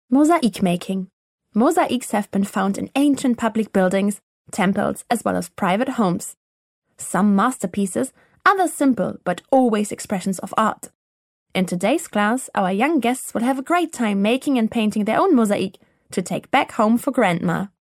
Voice Reel Showreel